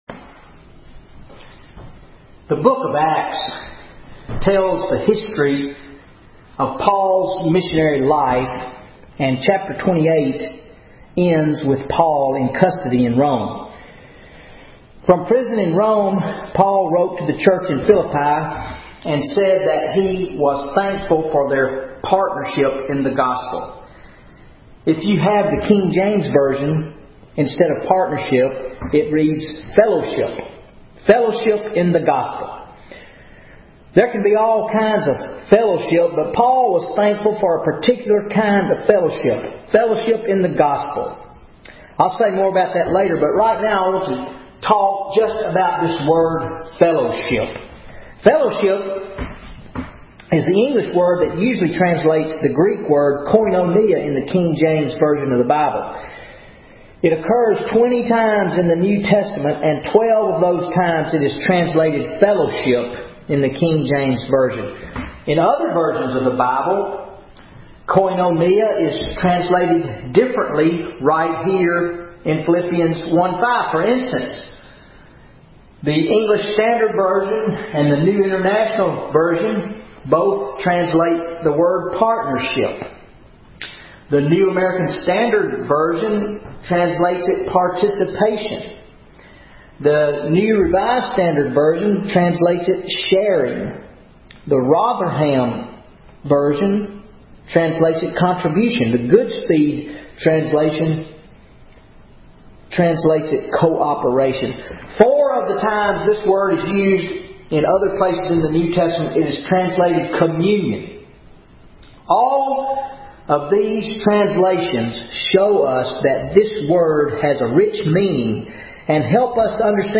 Wednesday Night Bible Study July 31, 2013 Philippians 1:3-5 What Is Fellowship in the Gospel?